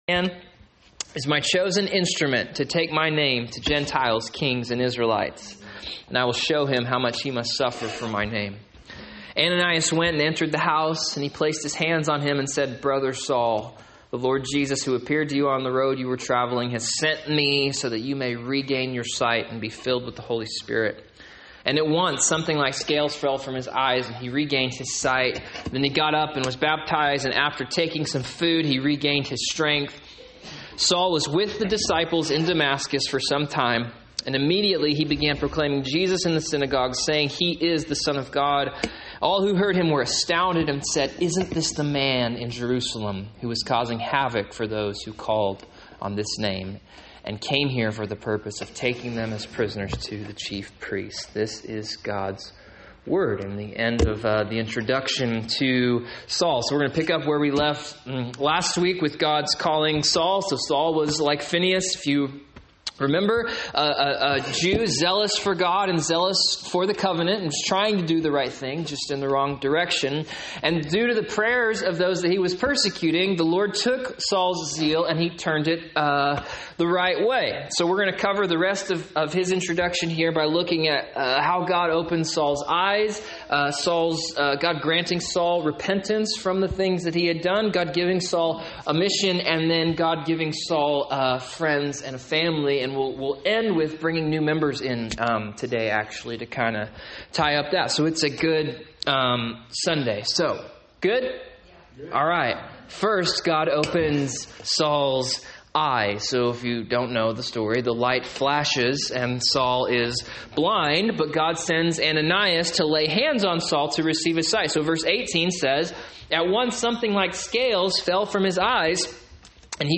Sermons | Christian Life Church